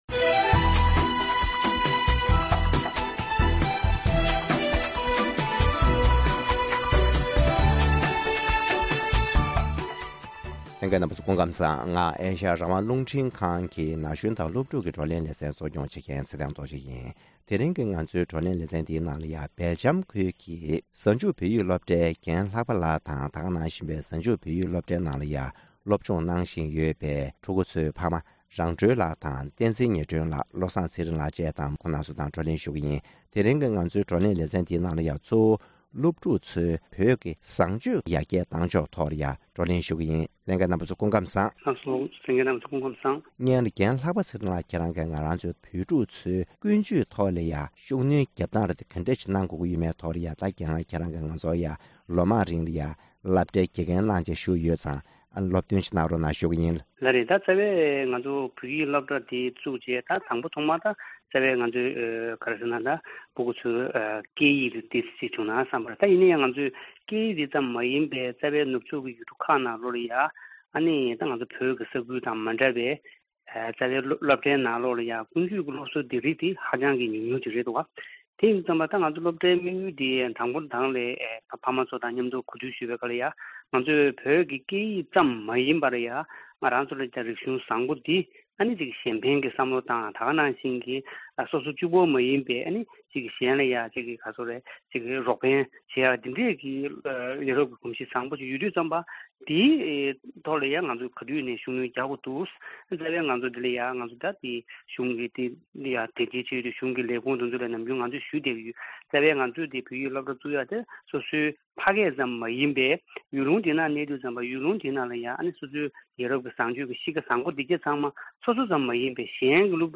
སྦལ་ཇམ་ཁུལ་གྱི་བོད་ཕྲུག་ཚོའི་ཀུན་སྤྱོད་སྔར་ལས་ཡག་ཏུ་གཏོང་རྒྱུའི་ཐབས་ལམ་སོགས་ཀྱི་ཐད་ལ་བགྲོ་གླེང་ཞུས་པ།